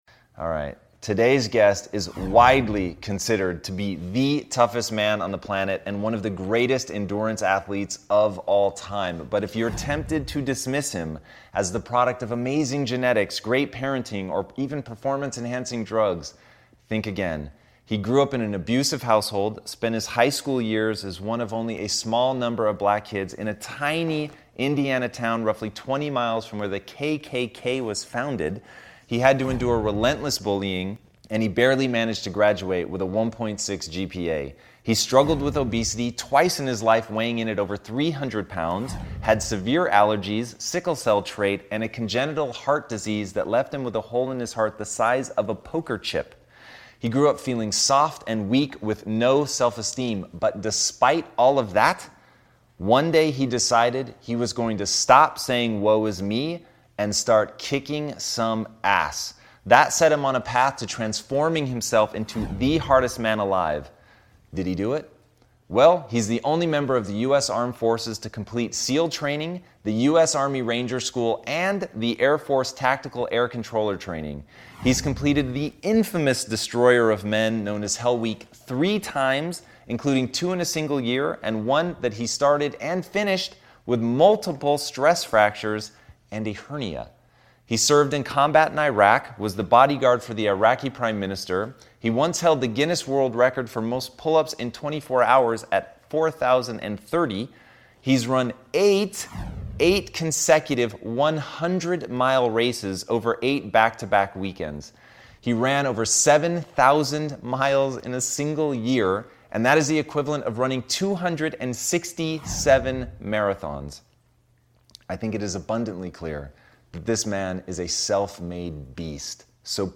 EP 29- David Goggins Interview- The world’s best ultra-endurance athlete- Ran 50 endurance races, Navy Seal Army Ranger and all around Bad Ass